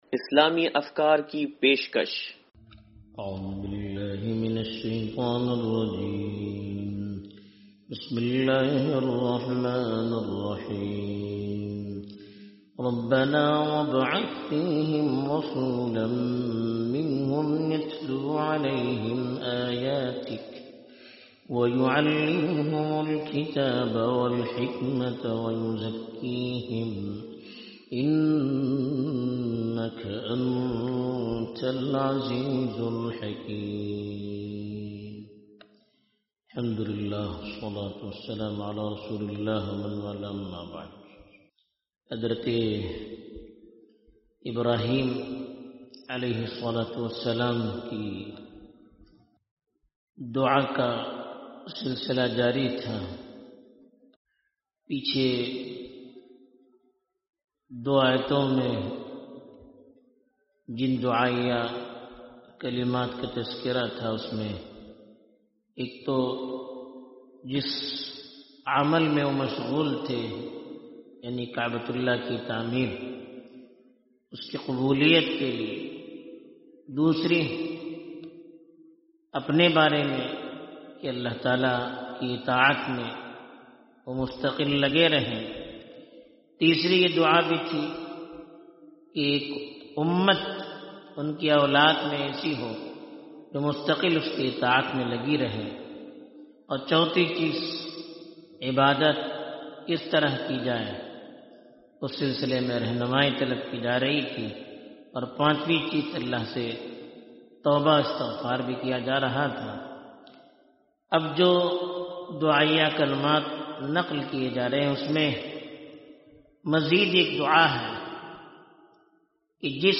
درس قرآن نمبر 0090